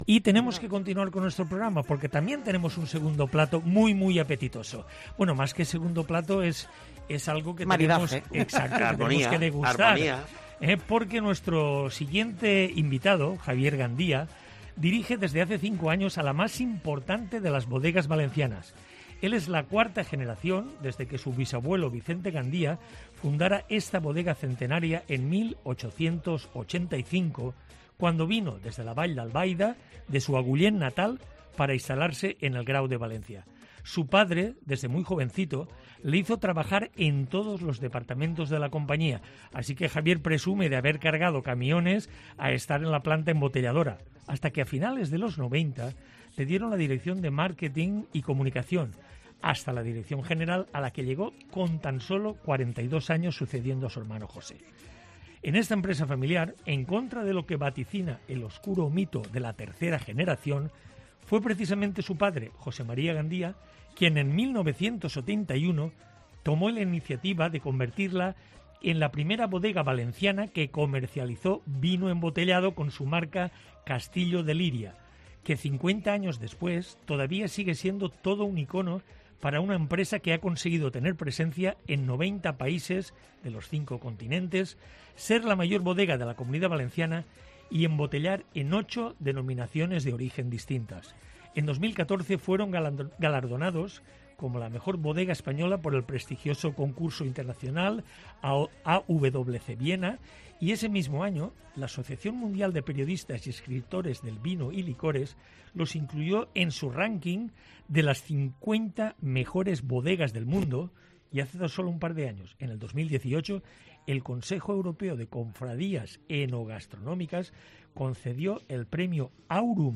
visita los micrófonos de GastroCOPE para hablar sobre este maravilloso aniversario